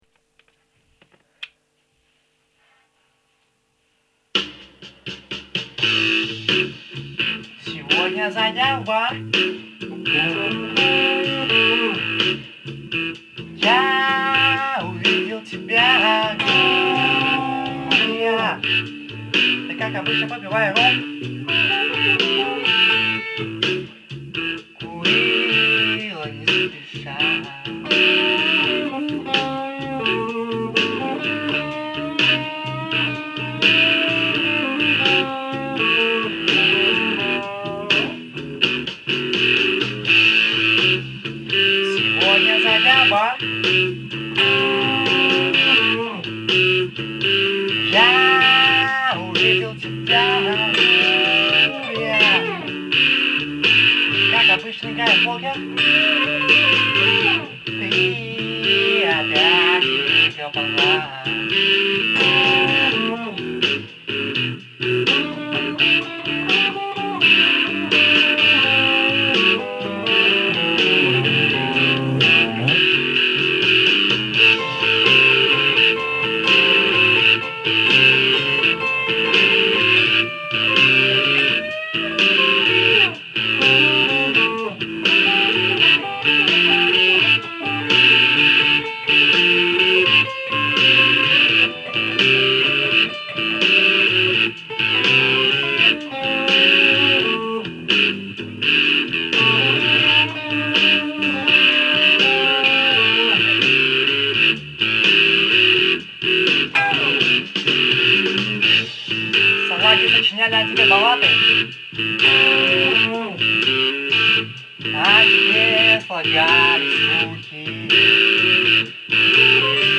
My blues improvisation.